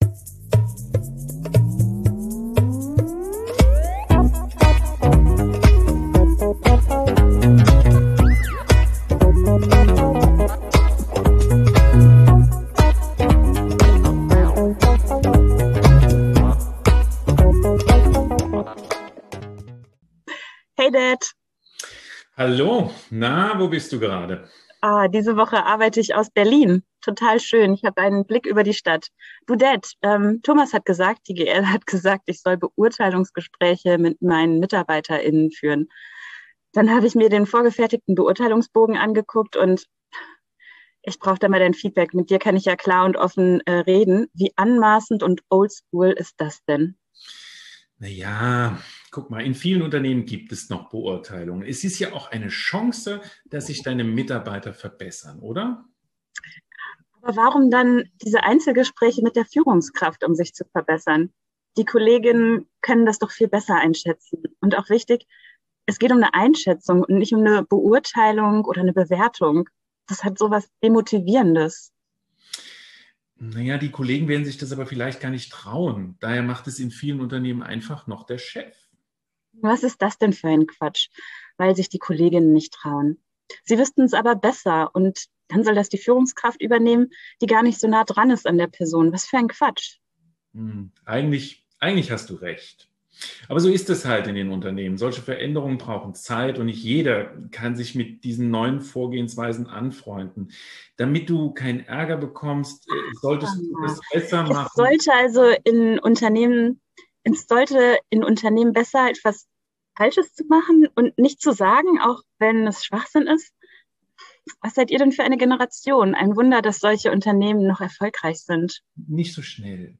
eine erwachsene Tochter, die in einem eher traditionell geprägtem Unternehmen eine neue Anstellung begonnen hat und hierüber mit Ihrem Vater
via Telefon diskutiert.